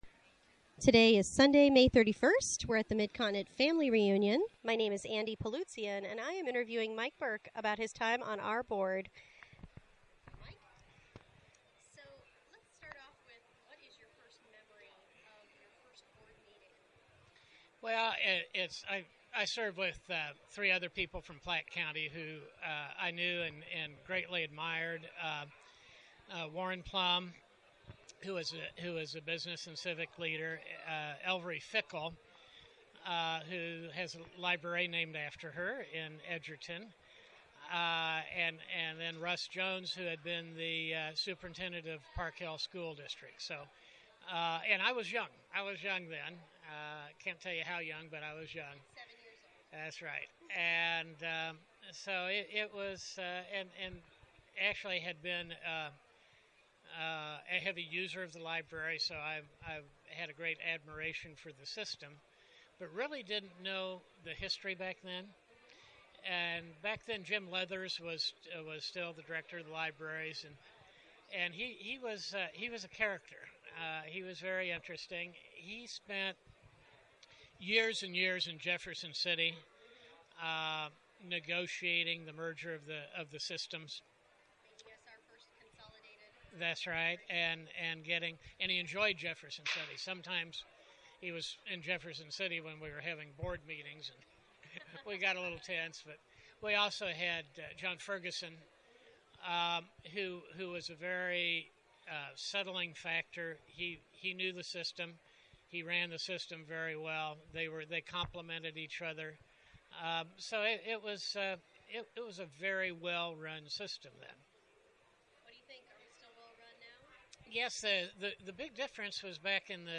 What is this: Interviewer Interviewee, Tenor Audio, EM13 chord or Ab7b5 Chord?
Interviewer Interviewee